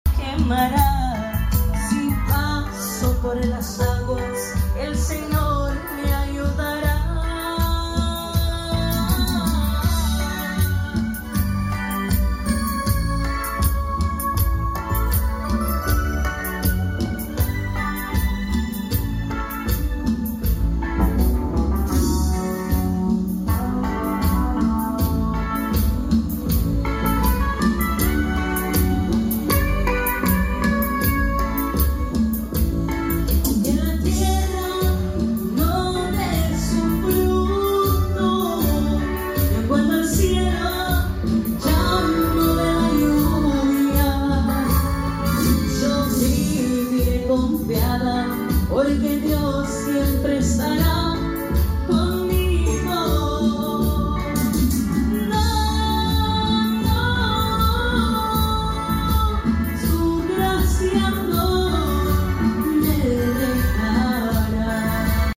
PRUEVA DE SONIDO. BODA RANCHO Sound Effects Free Download
Upload By MINISTERIO ALAS DE FÉ